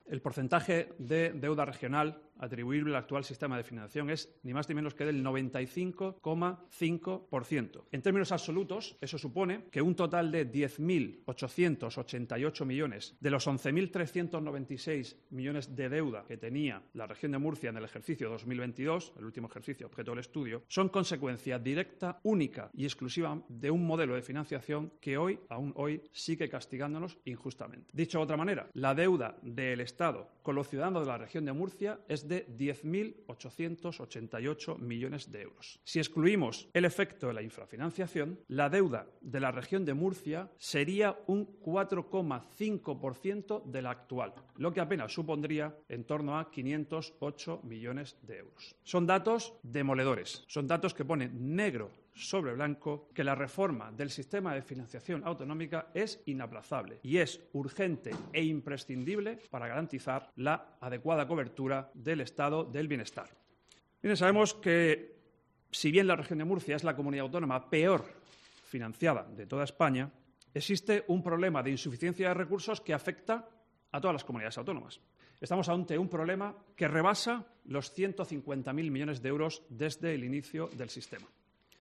Luis Alberto Marín, consejero de Economía, Hacienda y Empresa